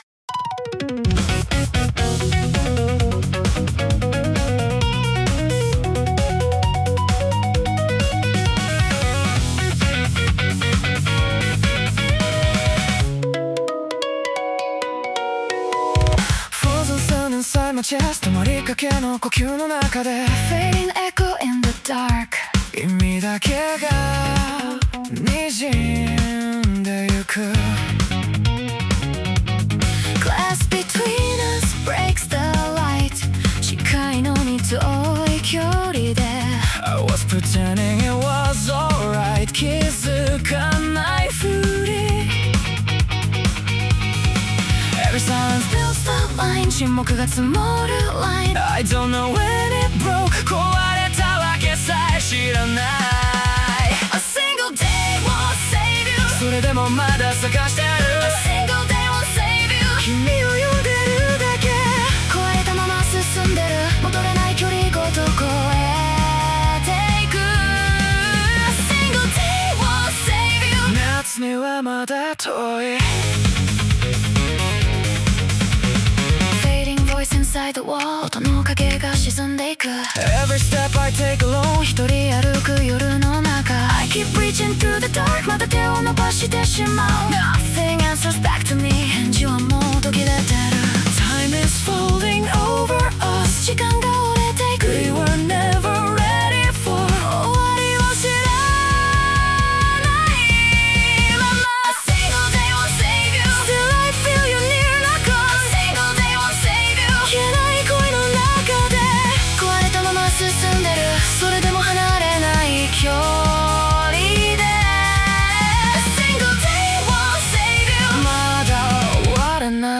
デュエット